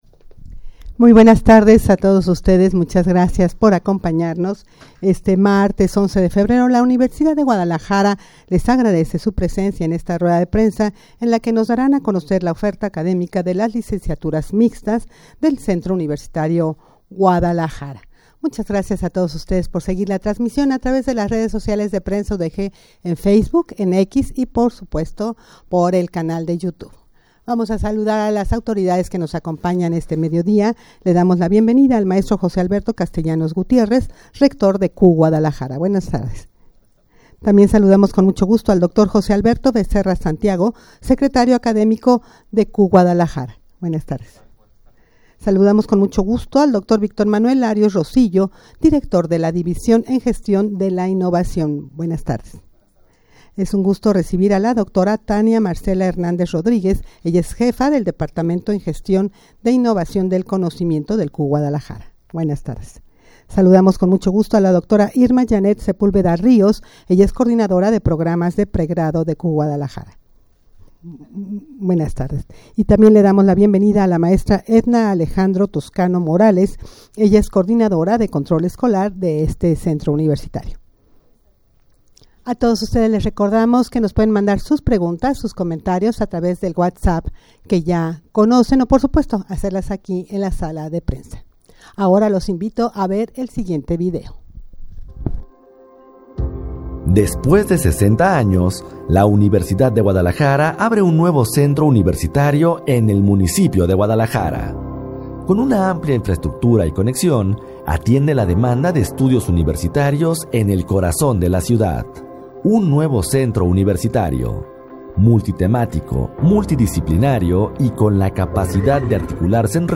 Audio de la Rueda de Prensa
rueda-de-prensa-para-dar-a-conocer-la-oferta-academica-de-las-licenciaturas-mixtas-del-campus.mp3